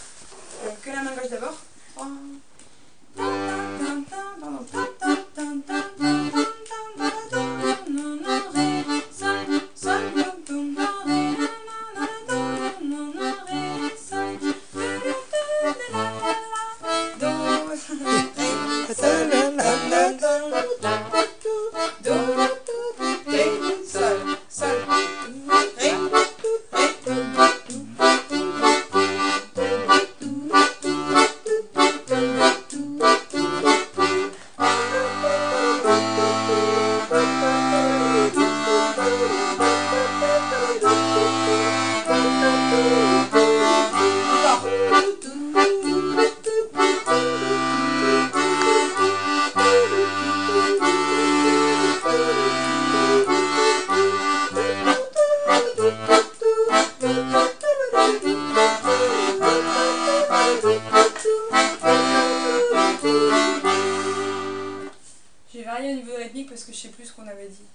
l'atelier d'accordéon diatonique
a mama accompagnement main gauche,
a mama accompagnement MG.mp3